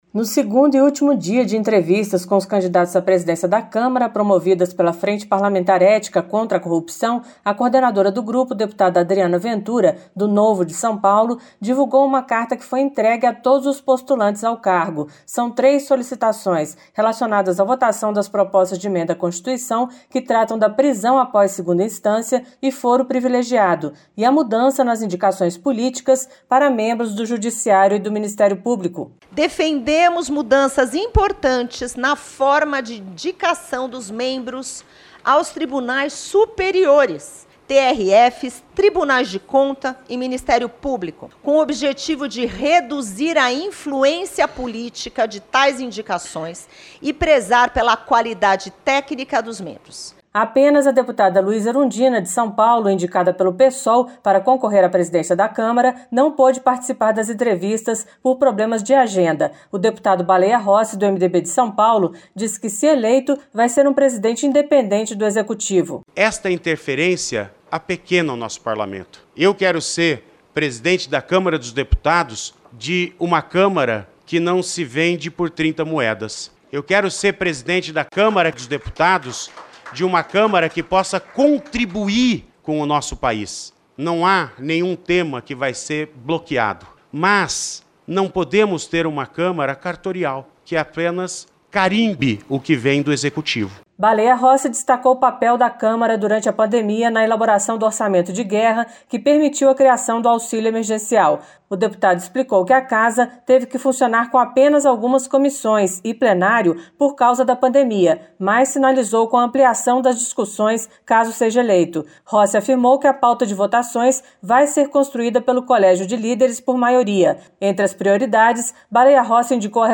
Candidatos à Presidência da Casa participaram de entrevistas promovidas pela Frente Parlamentar Ética Contra a Corrupção